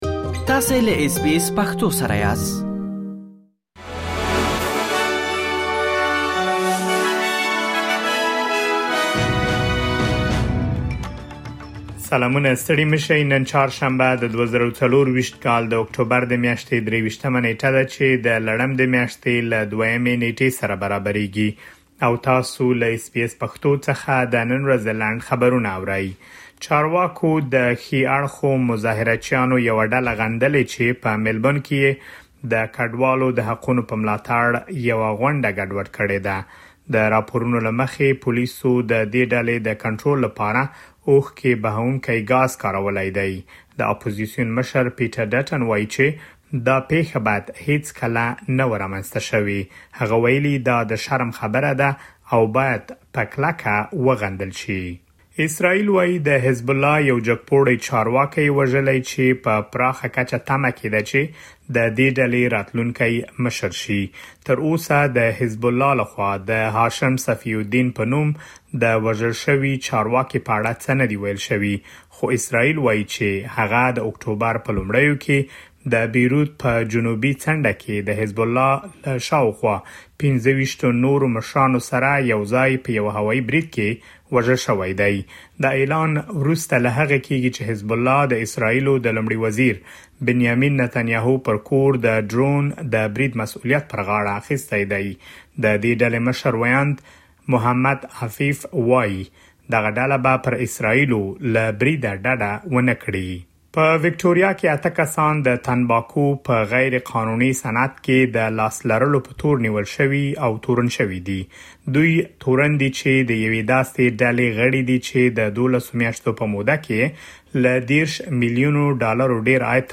د اس بي اس پښتو د نن ورځې لنډ خبرونه|۲۳ اکټوبر ۲۰۲۴